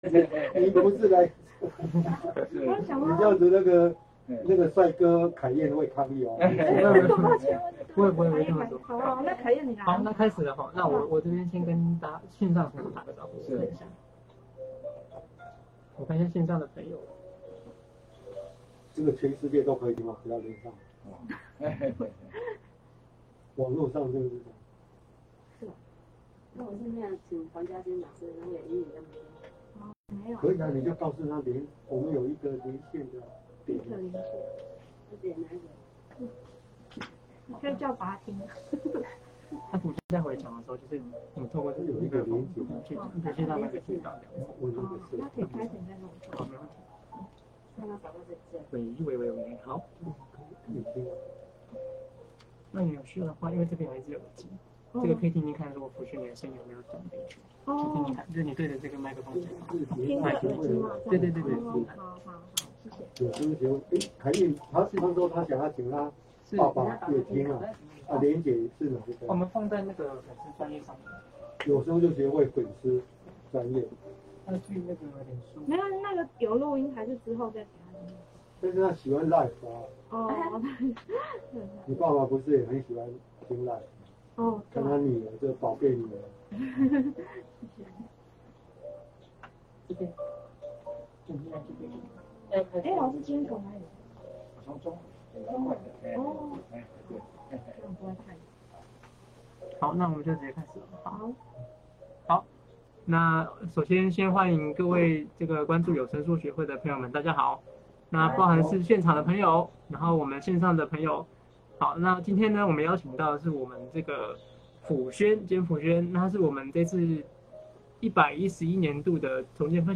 編者案：本單元取自於「有聲書學會」定期舉辦的「視障者經驗分享」座談會，現場實況連結如文末，內容包括從一開始出席聽眾的自我介紹、主講者的人生故事和最後的提問，希望帶領讀者參與一場充實精彩的講座。